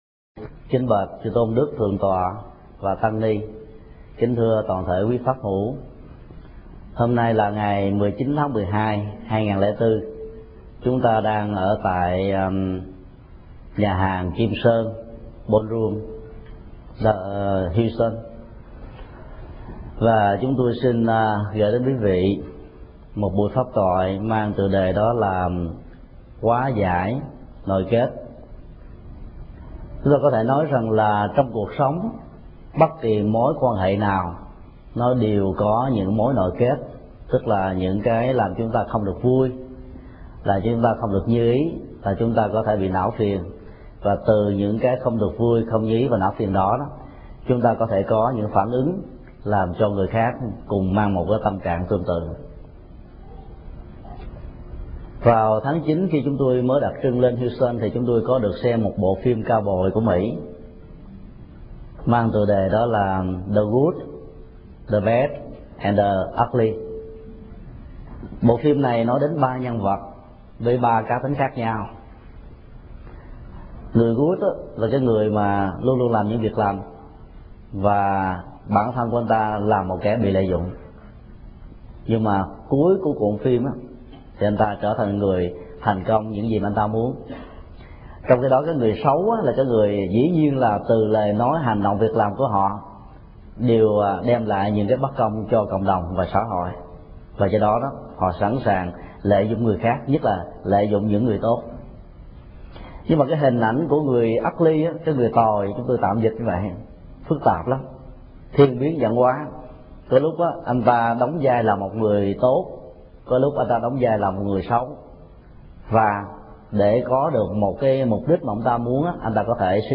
Tải mp3 Pháp Thoại Hóa giải nội kết – Thầy Thích Nhật Từ Nhà hàng Kim Sơn, Houston, ngày 19 tháng 12 năm 2004